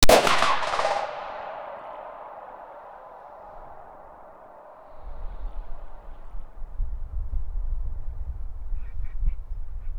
Royalty-free cannon sound effects
No reverb or echo. 0:10 Shot of Schwerer Gustav German 80-centimetre gun 0:10
shot-of-schwerer-gustav-g-nt7zgitg.wav